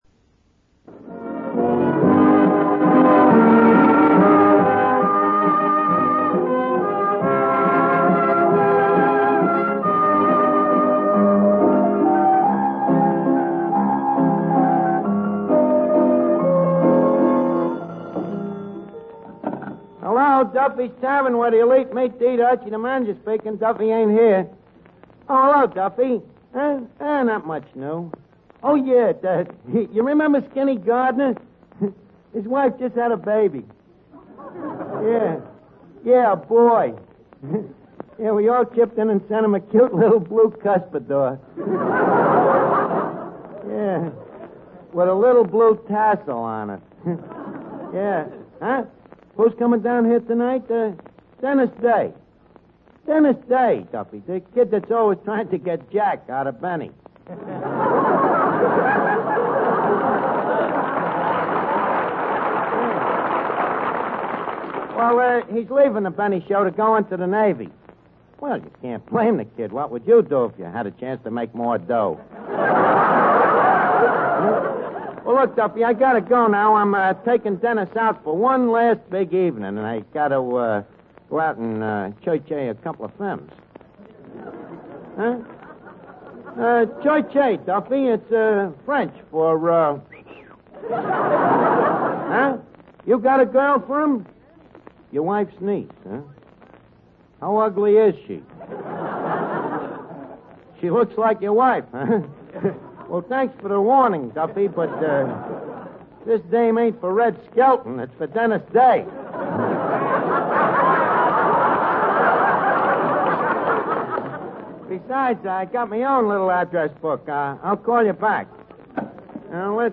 Duffy's Tavern Radio Program, Starring Ed Gardner